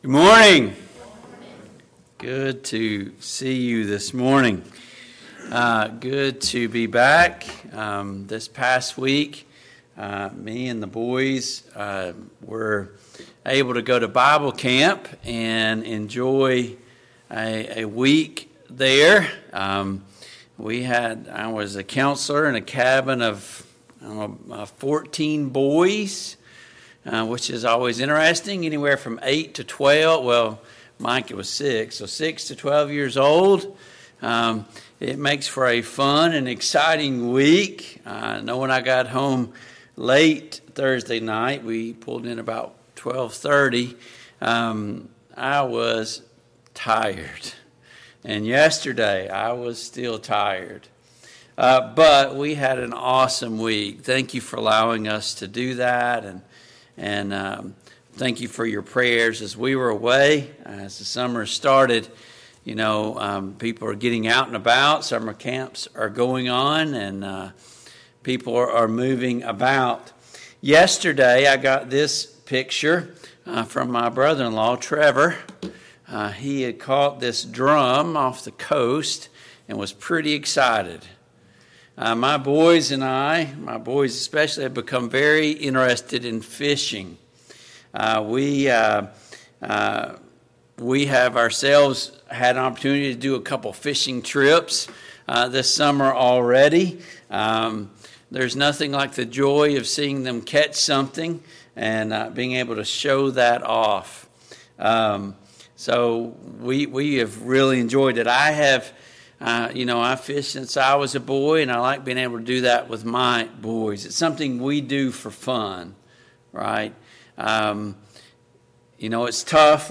Passage: John 6:1-14 Service Type: AM Worship Download Files Notes « 4.